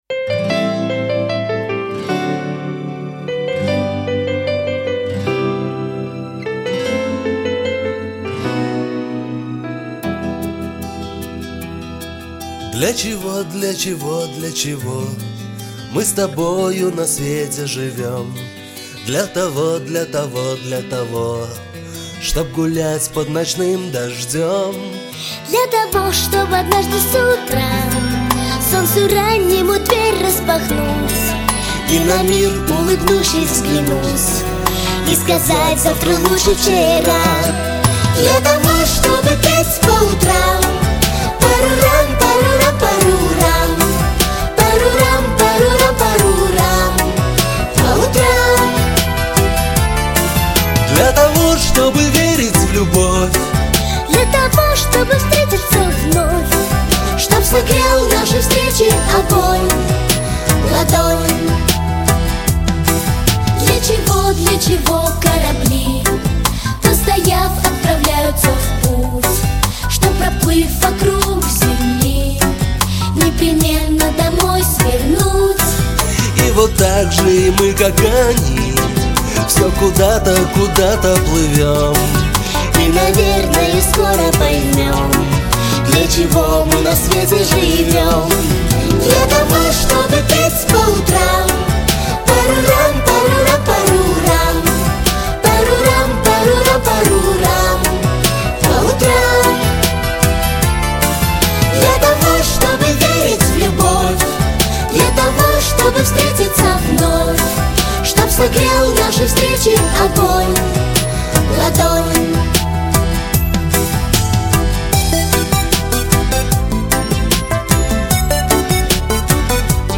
Главная / Песни для детей / Песни про Родину и Мир